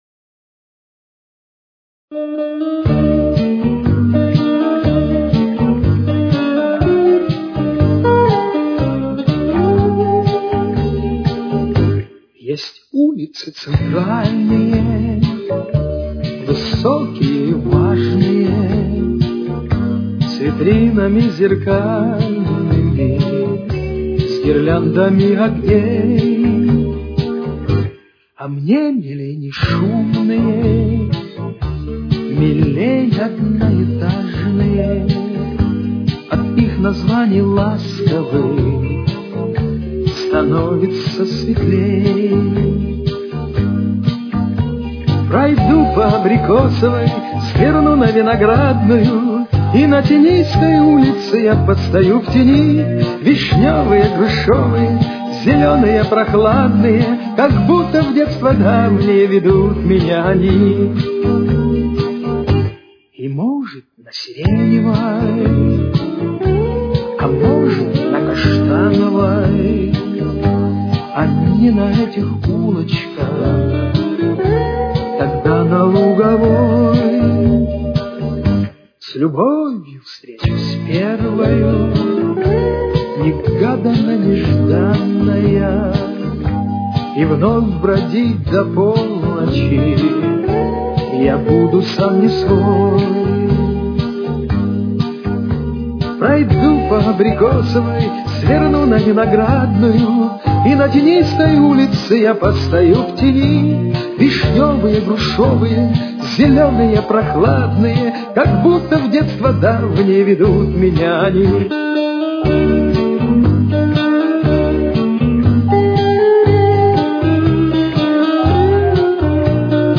Темп: 120.